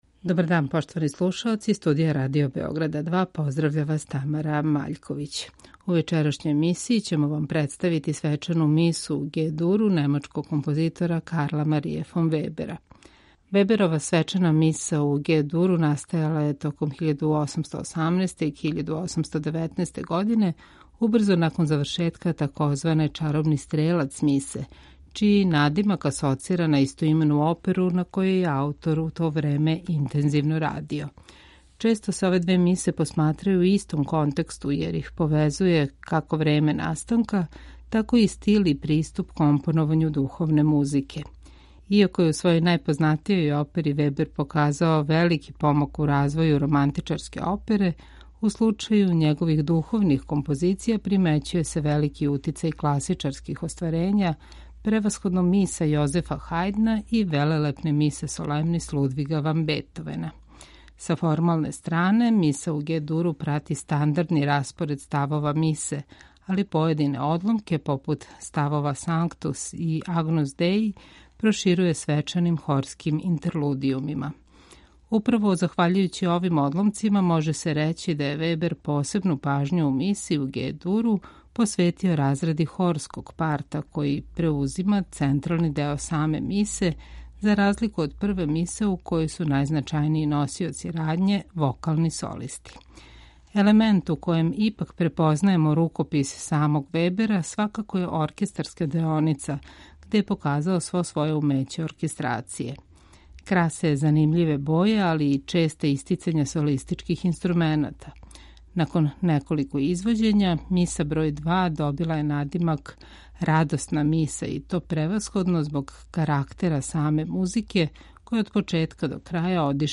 Отуд не чуди велики утицај оперског стила у овој миси.